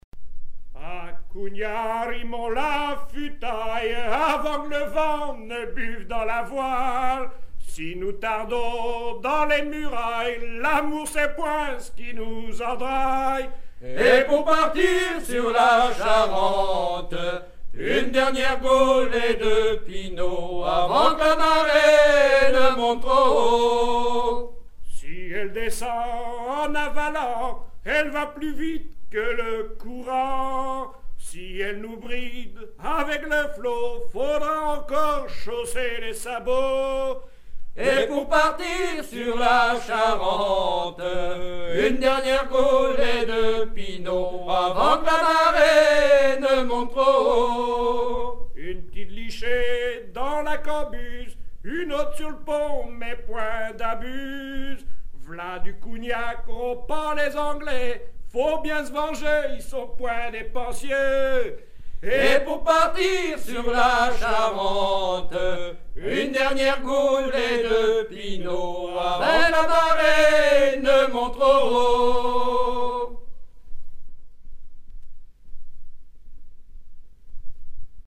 Chants de mariniers
Pièce musicale éditée